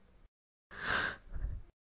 抱负女声
描述：这个声音是一种愿望（女性的声音）。它表示惊讶。 Este sonido esunaaspiración（voz femenina）。 Expresa sorpresa。
Tag: 女性语音 语音 误吸 惊喜 呼吸